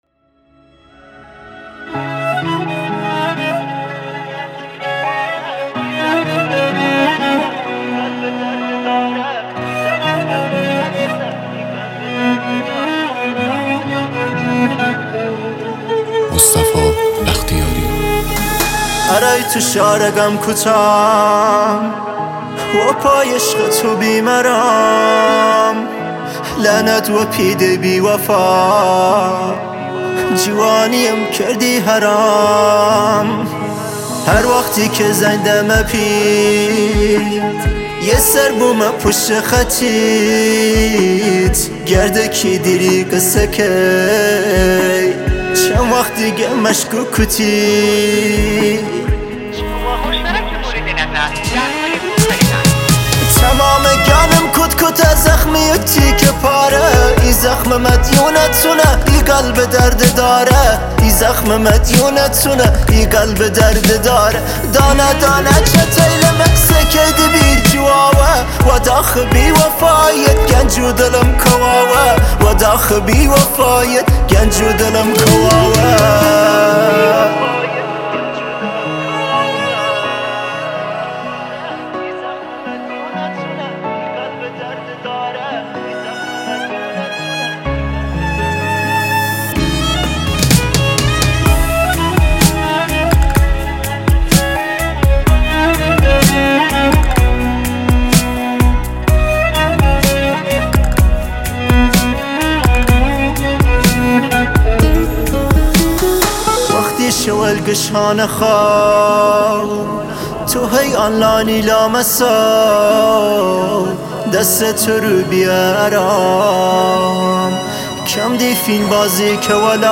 آهنگ کردی غمگین